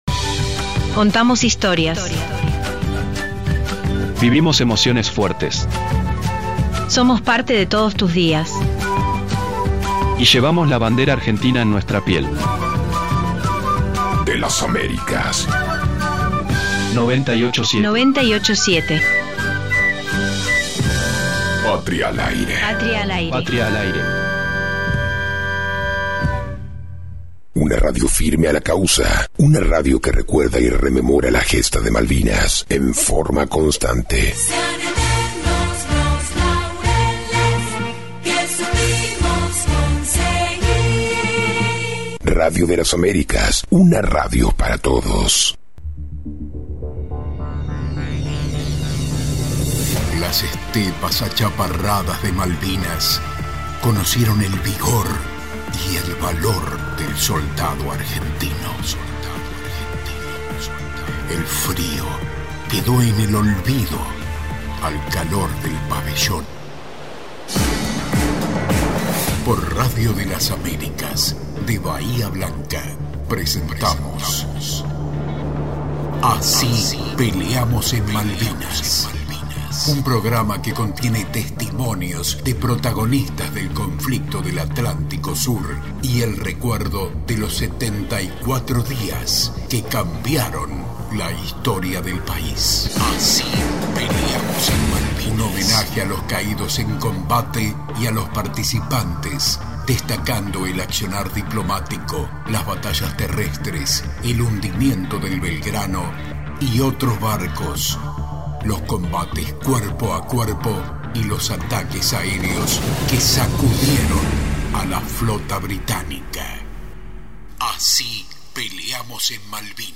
Entrevista radial actualidad de Malvinas y geopolítica del Atlántico Sur